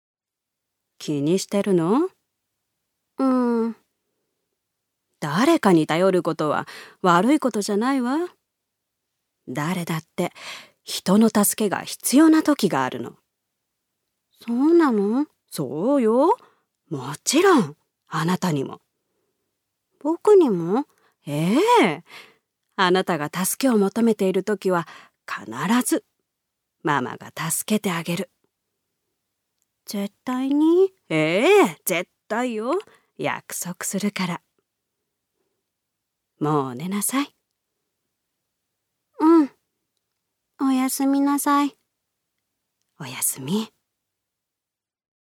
女性タレント
音声サンプル
セリフ３